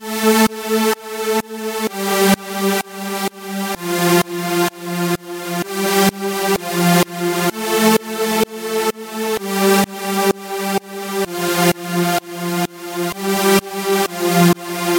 弓箭手合成器铅字
Tag: 122 bpm Dance Loops Synth Loops 1.32 MB wav Key : C